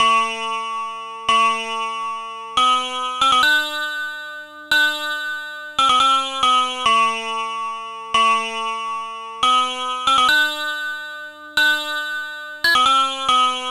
Exodus - Clavi Hit.wav